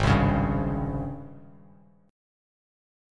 G#M11 chord